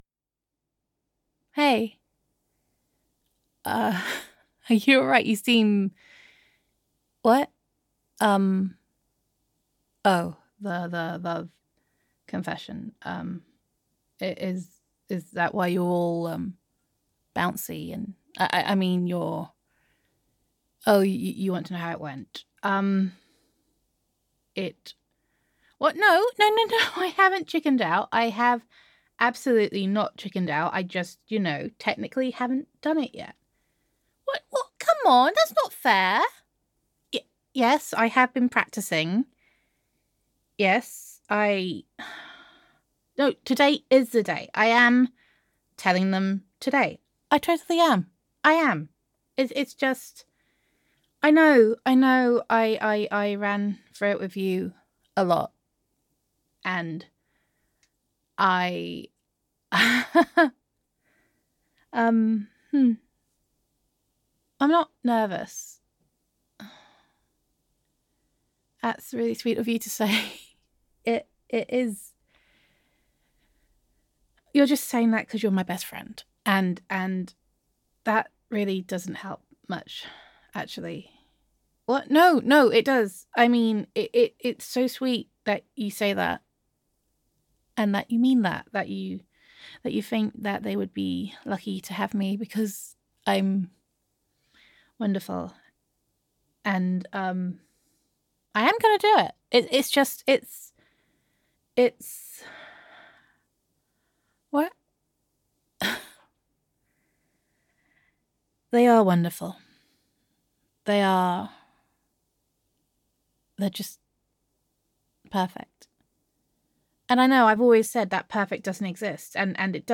[F4A] Practice Makes Perfect [Best Friend Roleplay][Friends to Lovers][Love Confession][Adorable Idiot][Oh Bum][Chickening out][Adorkable][Nervousness][Being Super Dodge][Gender Neutral][Your Adorkable Best Friend Nervously Confesses Her Love]